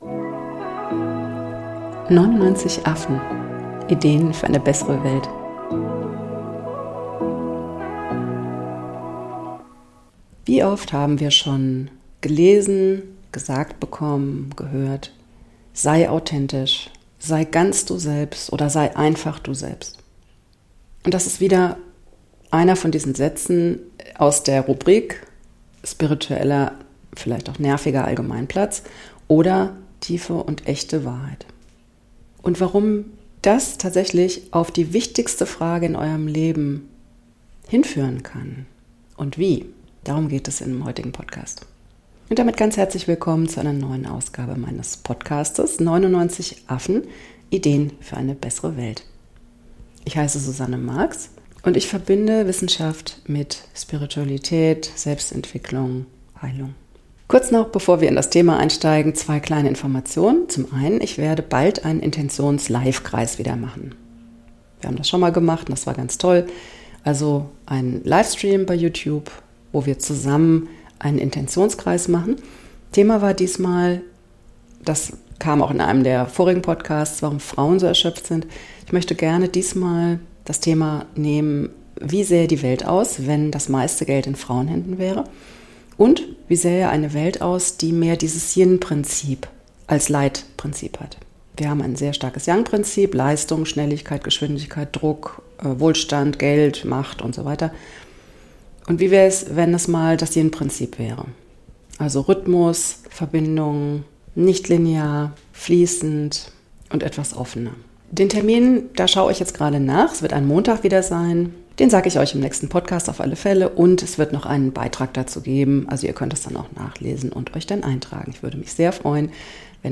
Warum aber genau diese Sätze tatsächlich lebensverändernd sein können und wie das geht, das erfahrt ihr in dieser Folge. P.S.: Sorry für die Hintergeräusche - es ist Herbst und damit Laubbläser-Time :))